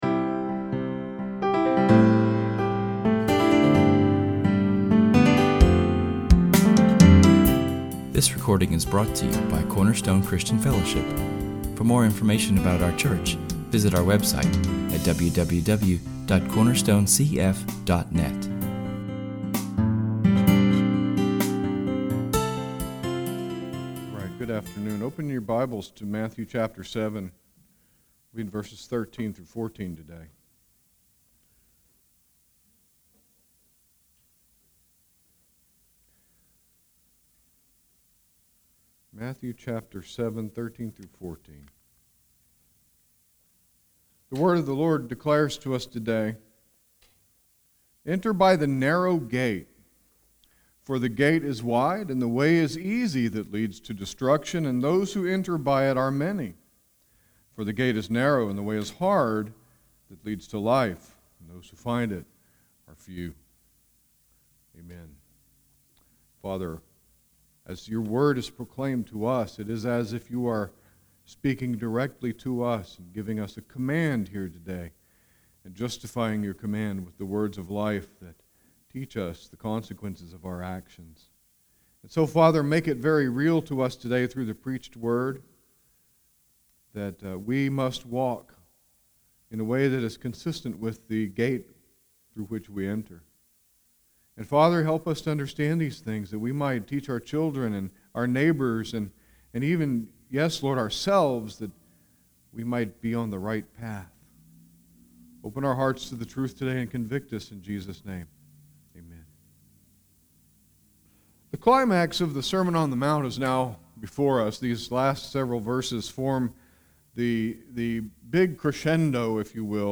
Public reading: [esvignore]Psalm 1[/esvignore]; Benediction: [esvignore]Romans 15:13[/esvignore] Matthew 7:13-14 Psalm 1 Romans 15:13